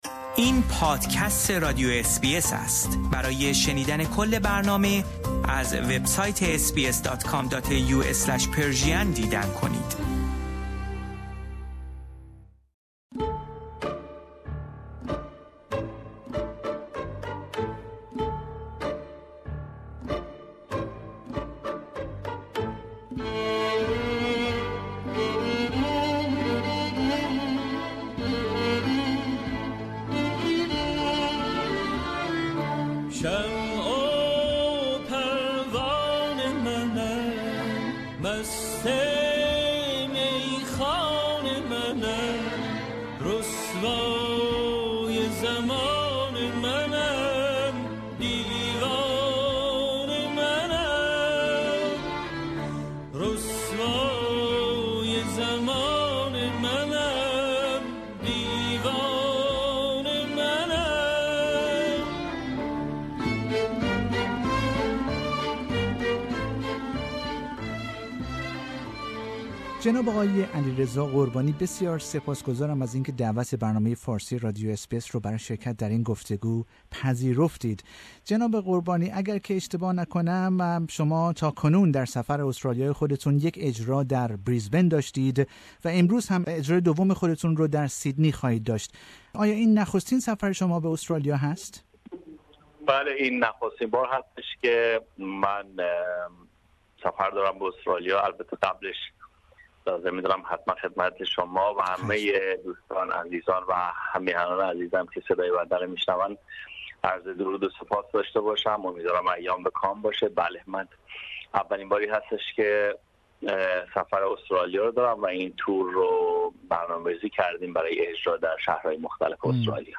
در گفتگویی با آقای قربانی که به تازگی و برای نخستین بار برای انجام کنسرت هایی به استرالیا آمده در خصوص موسیقی اصیل ایرانی و فراز و فرودهایش ظرف چهار دهه اخیر پرداخته ایم.